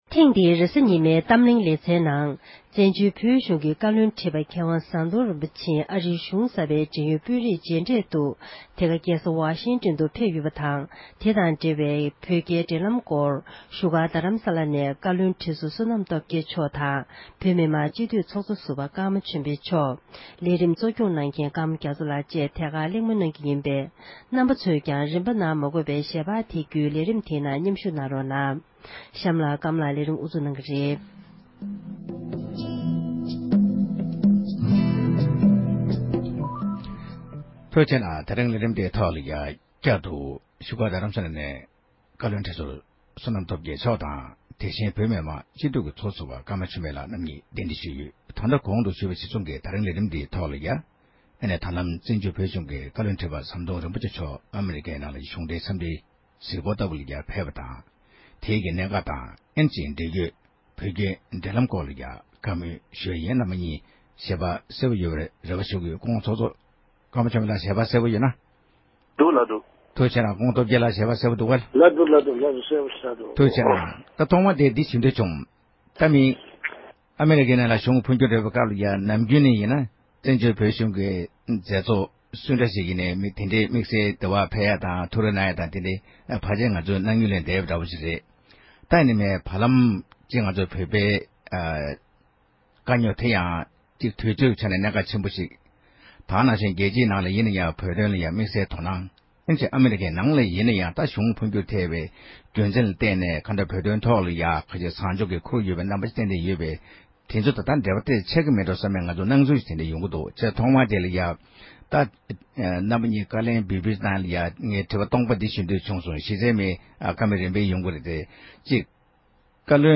གཏམ་གླེང